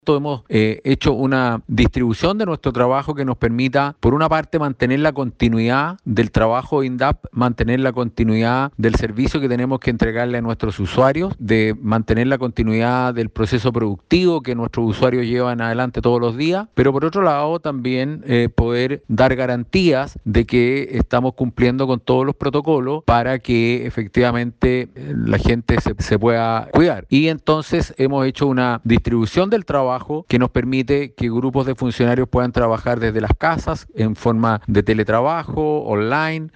Carlos Recondo, Director Nacional del Instituto de Desarrollo Agropecuario INDAP, en conversación con Campo al Día de Radio SAGO, sostuvo que la institución ha adoptado todas las medidas necesarias para enfrentar la difícil coyuntura mundial provocada por el COVID-19, y pese a tener a muchos funcionarios con teletrabajo, todas las Direcciones Regionales trabajan para mantener funcionando el sistema en el país.